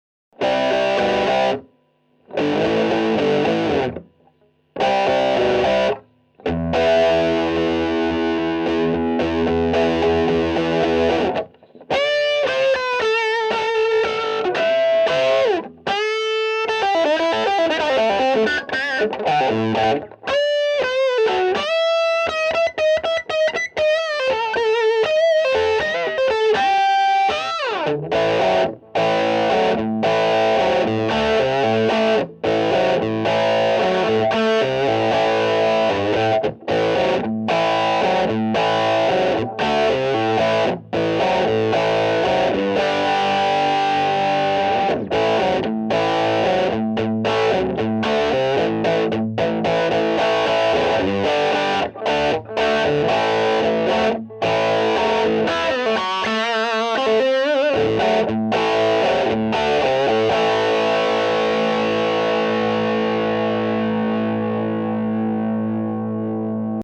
voici donc un petit riff de gratte, tout nu tout cru (la prise de base) :